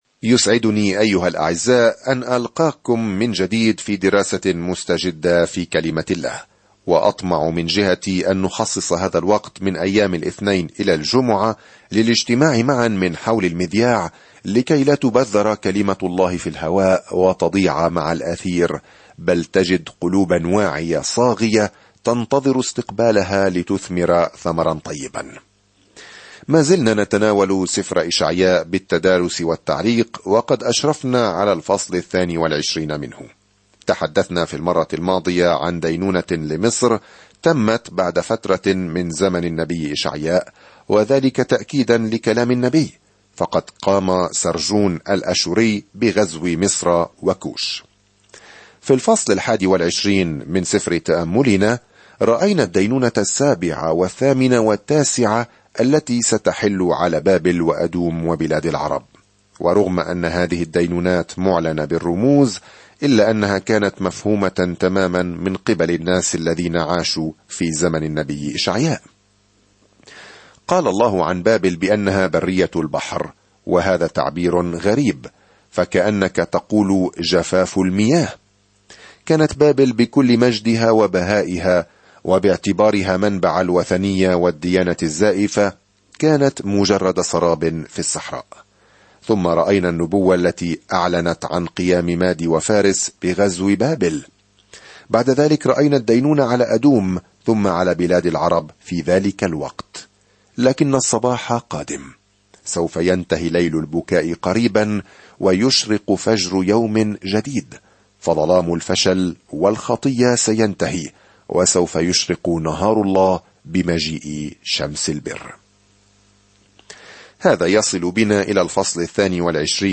الكلمة إِشَعْيَاءَ 22 إِشَعْيَاءَ 1:23 يوم 21 ابدأ هذه الخطة يوم 23 عن هذه الخطة ويصف إشعياء، المسمى "الإنجيل الخامس"، ملكًا وخادمًا قادمًا "سيحمل خطايا كثيرين" في وقت مظلم عندما يسيطر الأعداء السياسيون على يهوذا. سافر يوميًا عبر إشعياء وأنت تستمع إلى الدراسة الصوتية وتقرأ آيات مختارة من كلمة الله.